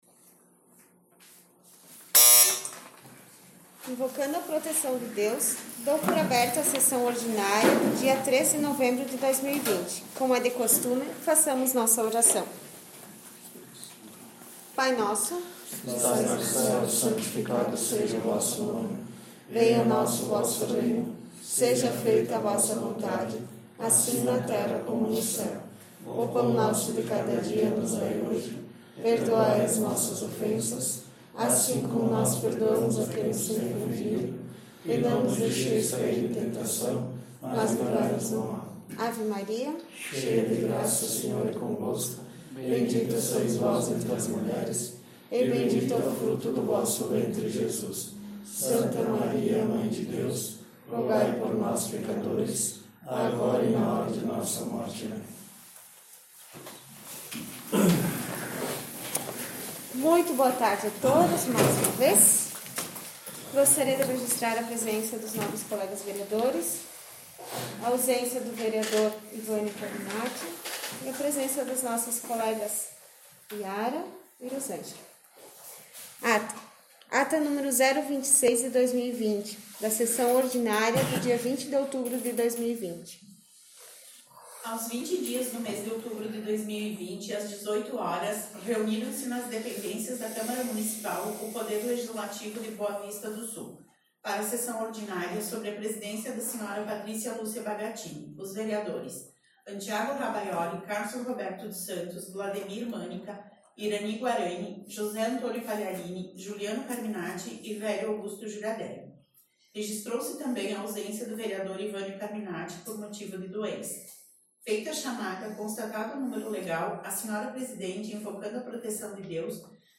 Sessão Ordinária 03/11/2020